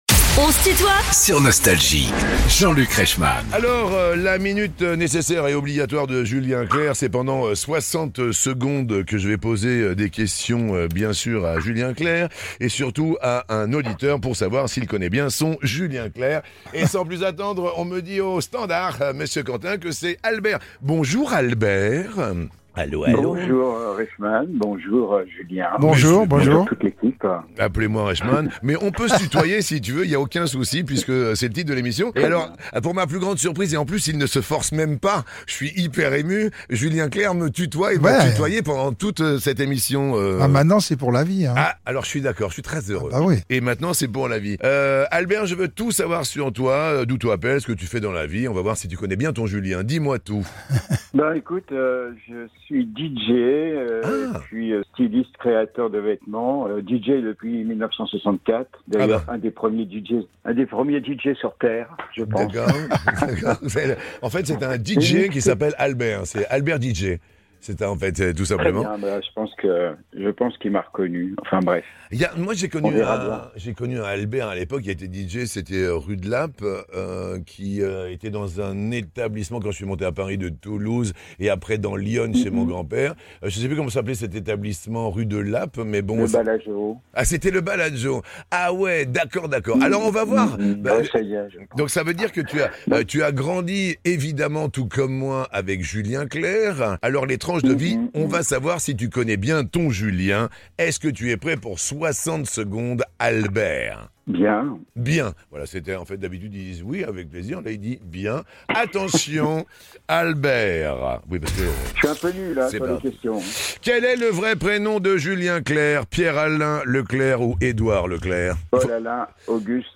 Julien Clerc est l'invitée de "On se tutoie ?..." avec Jean-Luc Reichmann